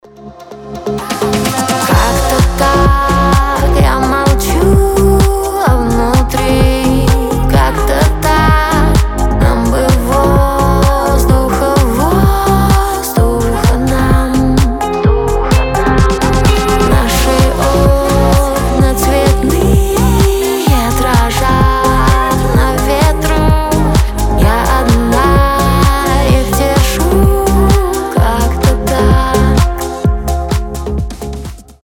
• Качество: 320, Stereo
приятные
красивый женский голос
нежные